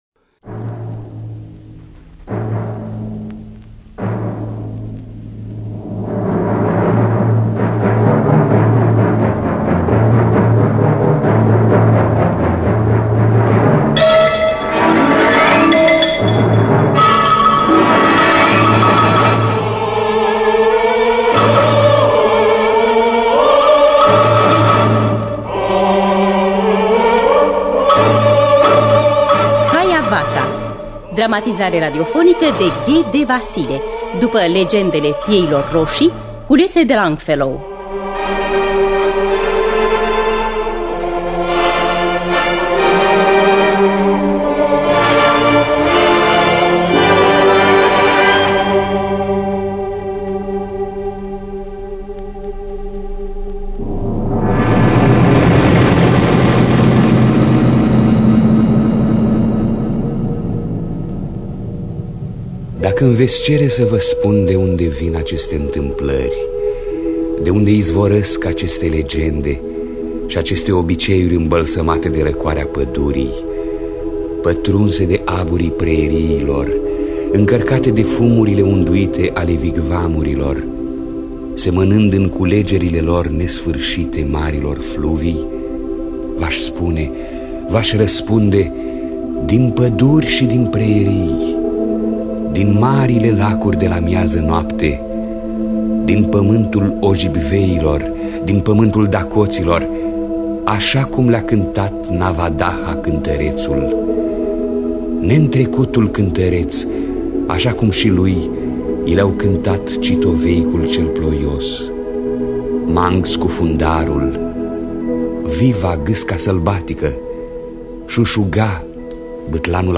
Haiavatha de Henry Wadsworth Longfellow – Teatru Radiofonic Online
Înregistrare din anul 1973.